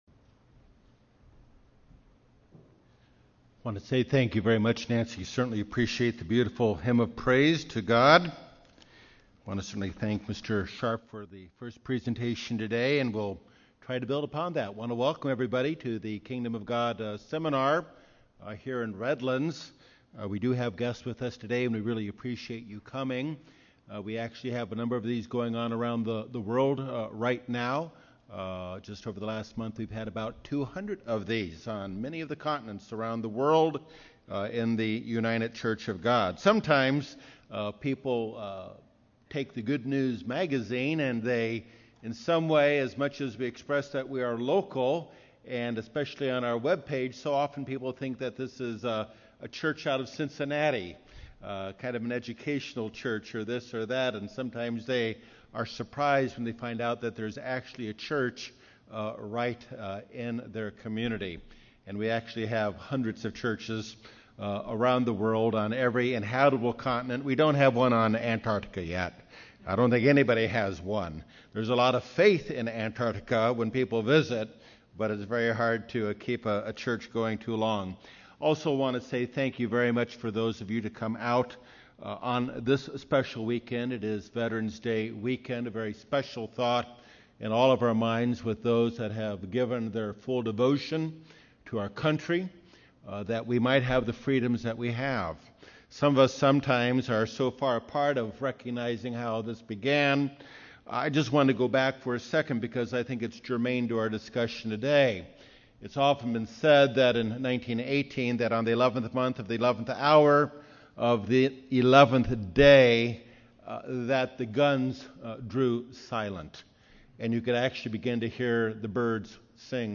This is a message about Jesus coming and being touched by humanity and helping us become a new creation. We are told how we are to hand over our future as well as giving up our old past. Learn more in this Kingdom of God seminar.